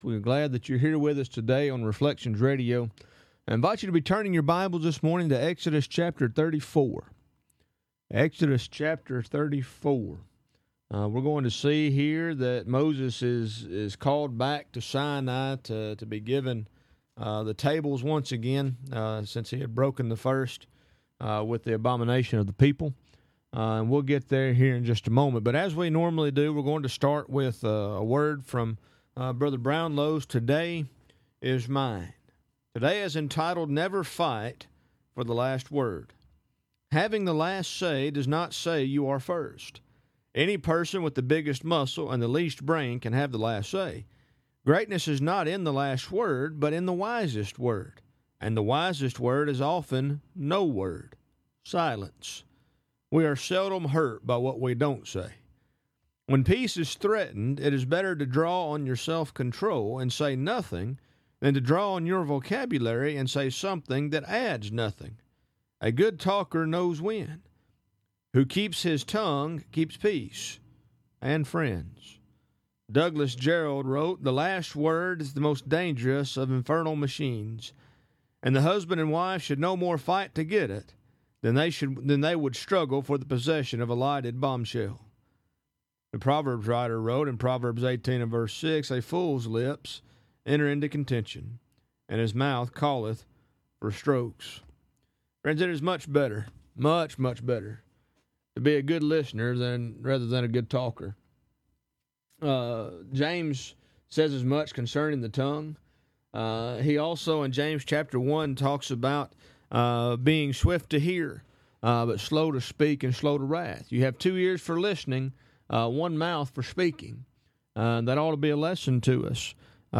Program Info: Live program from the Nesbit church of Christ in Nesbit, MS.